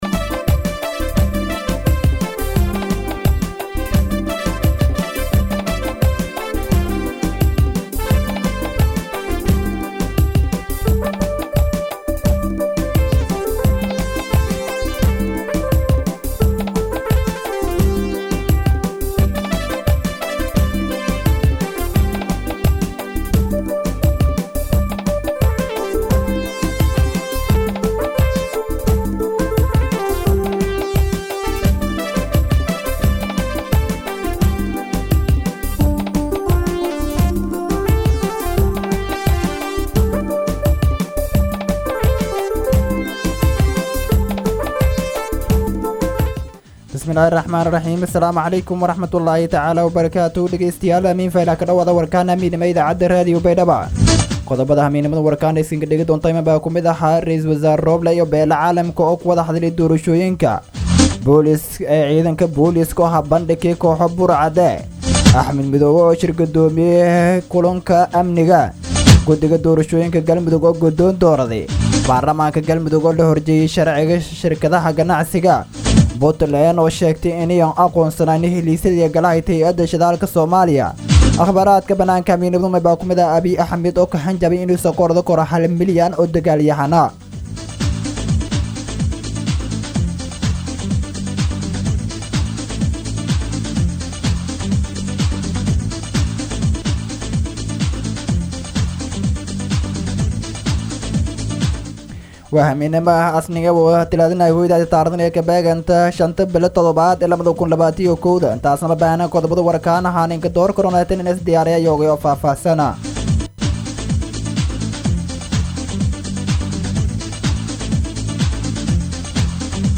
DHAGEYSO:- Warka Habeenimo Radio Baidoa 5-7-2021